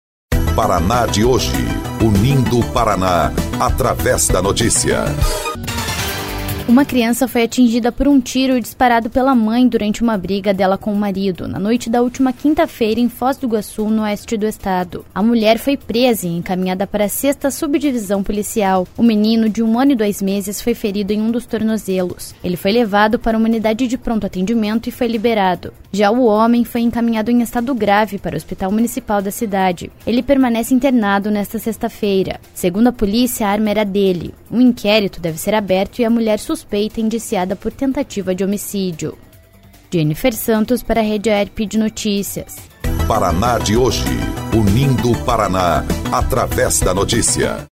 18.05 – BOLETIM – Criança é atingida por tiro disparado pela mãe durante briga com marido